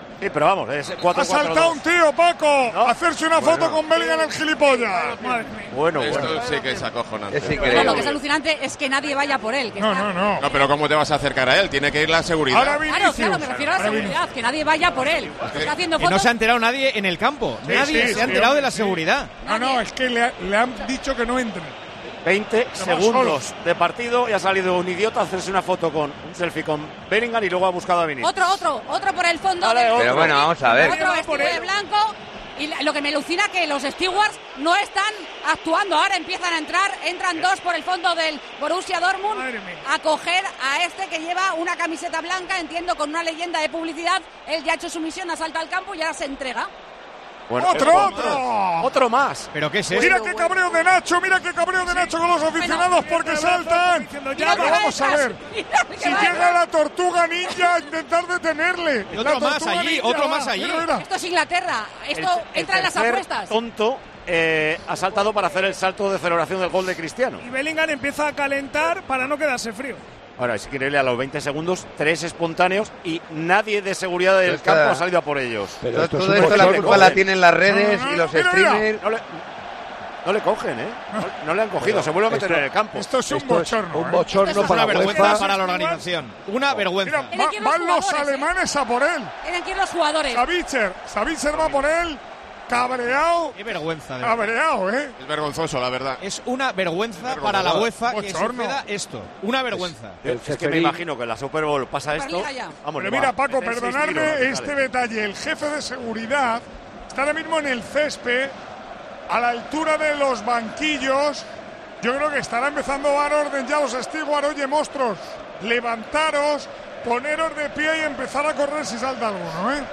Solo habían trascurridos 20 segundos de partido, cuando el equipo de Tiempo de Juego presente en Wembley con Manolo Lama, Paco González y Juanma Castaño a la cabeza avisaban de que un primer espontáneo había saltado al campo.